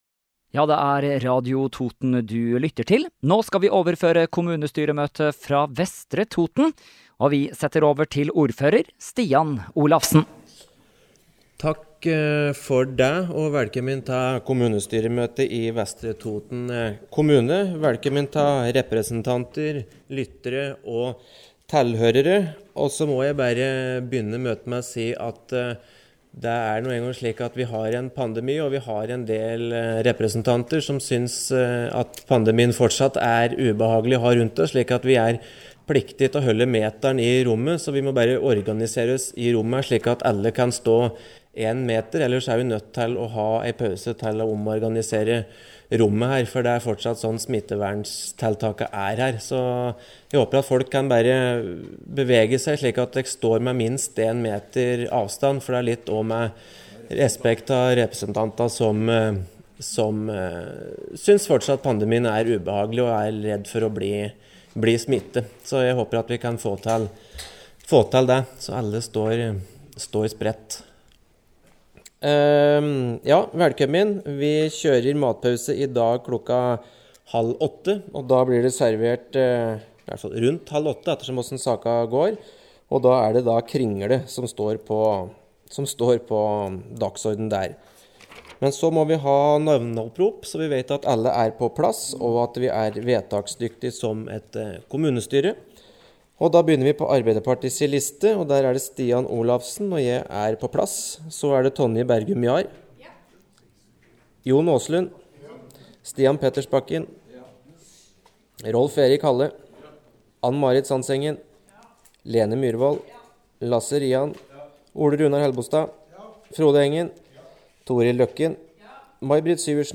Kommunestyremøte fra Vestre Toten 26. august – Lydfiler lagt ut | Radio Toten